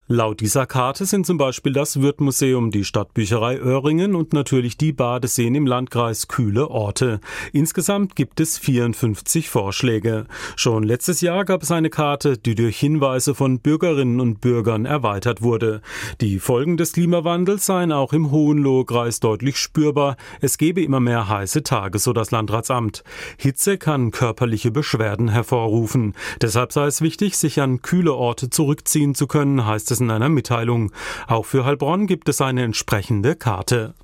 "Eis funktioniert natürlich immer an heißen Tagen oder ins Freibad gehen", sagt ein Passant in der Heilbronner Innenstadt.